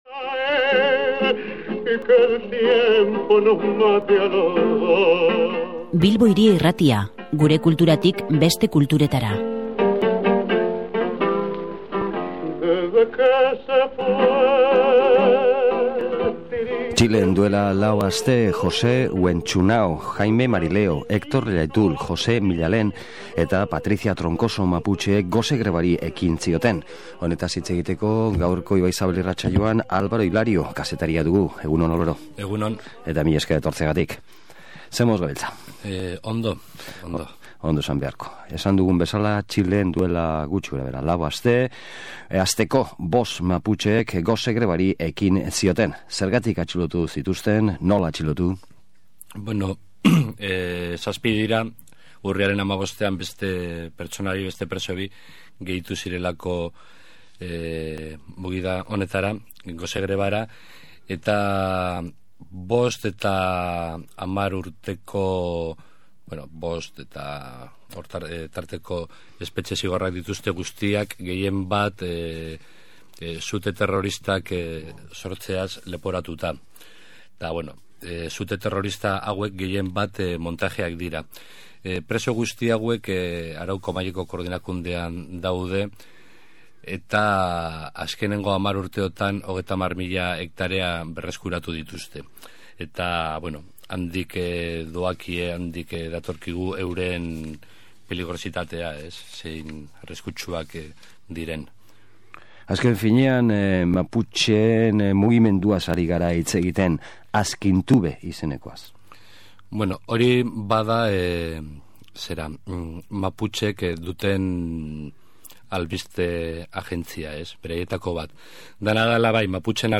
Multinazionalen aurkako “atentatuak” egiteagatik daude kondenatuta eta gose grebaren bitartez “epaiketaren muntaia” salatu nahi dute; bide batez, Txileko gobernuari egoera azaltzeko eta bere jarrera jakinarazteko eskatzen diote. Elkarrizketaren bigarren zatian